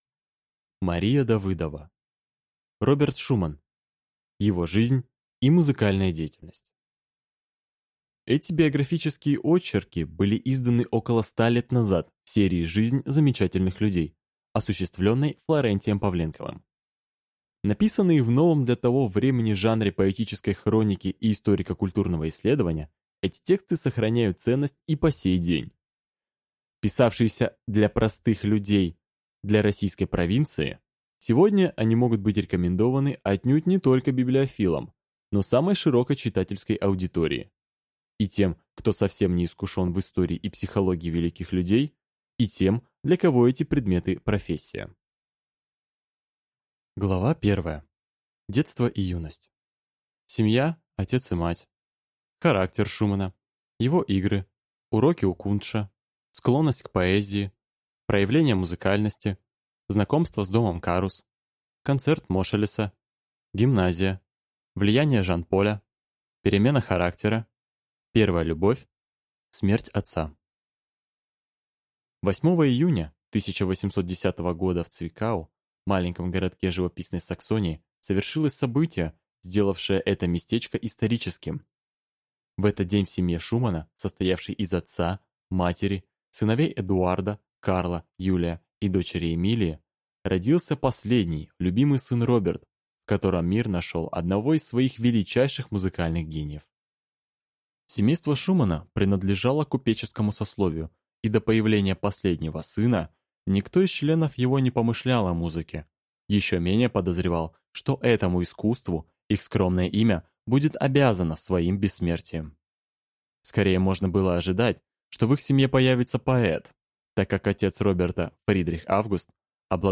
Аудиокнига Роберт Шуман. Его жизнь и музыкальная деятельность | Библиотека аудиокниг